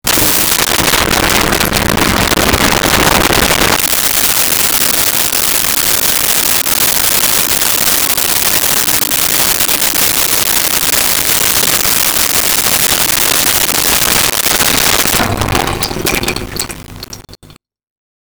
Water Drain 1
water-drain-1.wav